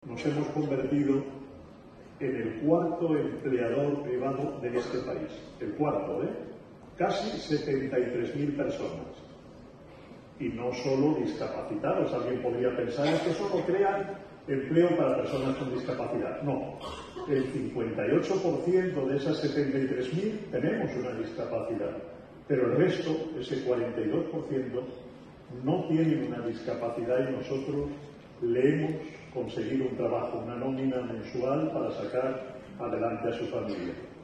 Foto de familia de los premiados junto a las autoridadesLa semana siguiente, el jueves 24 de octubre, fue la ONCE de Cantabria la que rindió homenaje al espíritu solidario de la sociedad montañesa con la entrega de sus galardones autonómicos 2019, en una gala celebrada en un abarrotado Teatro Casyc de Santander y presidida por los máximos responsables de la Comunidad Autónoma, Miguel Ángel Revilla, y del Grupo Social ONCE, Miguel Carballeda.